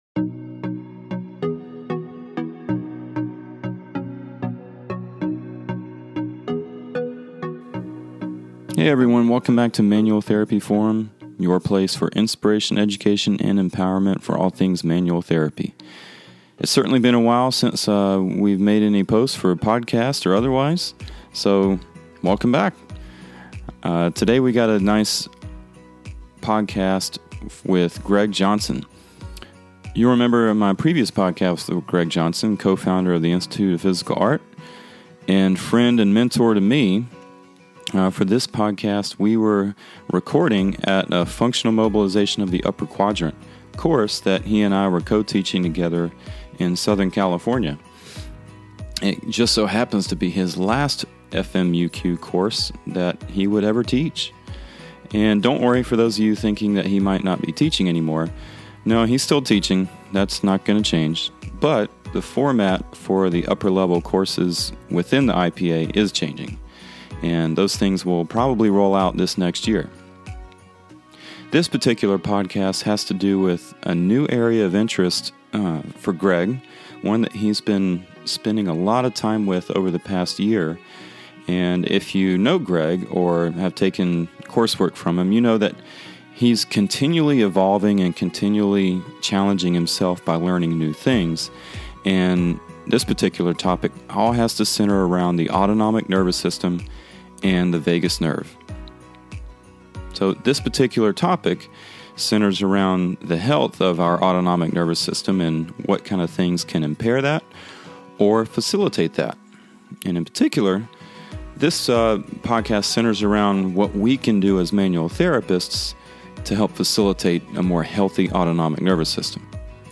When planning for this trip, I selfishly packed my podcast equipment in hopes we could sit down and record a conversation.